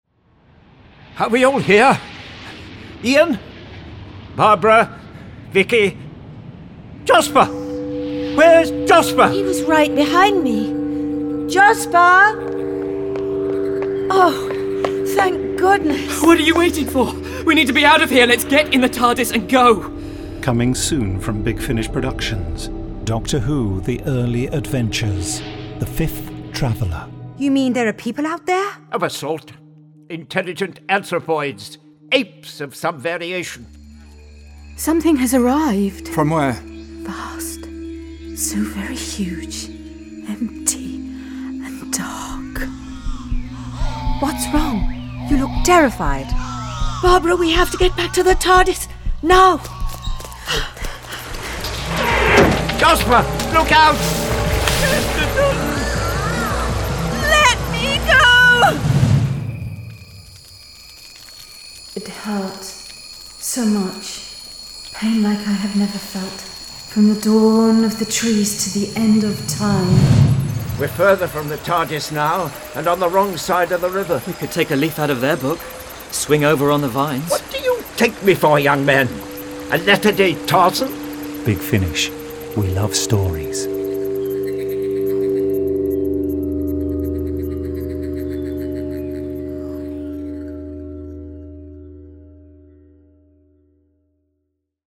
full-cast original audio dramas
Starring William Russell Maureen O'Brien